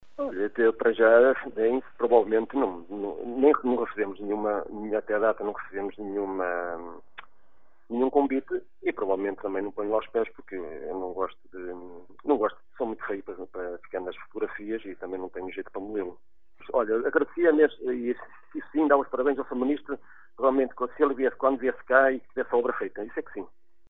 À Rádio Caminha, o presidente da Junta de Vila Praia de Âncora diz que prefere dar os parabéns ao ministro quando a obra estiver concluída